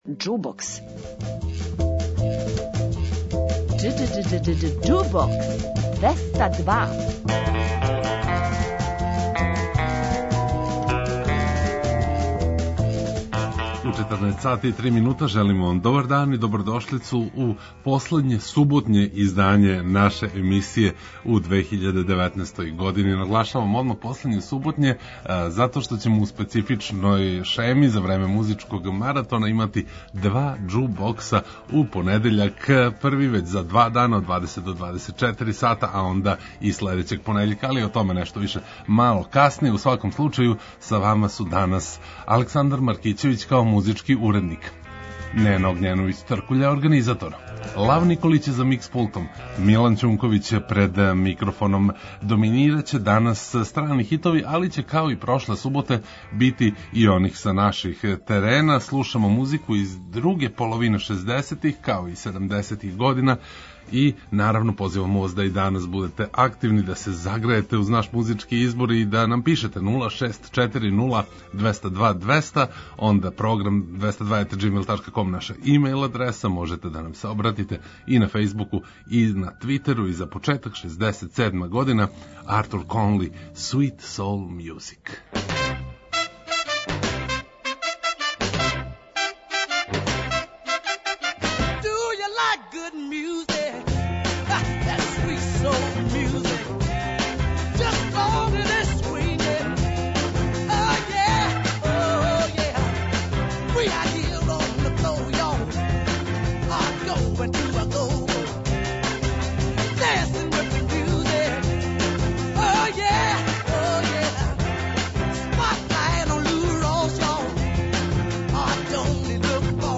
Многима ће, сасвим сигурно, пријати загревање за Нову годину уз старе хитове, и домаће и стране.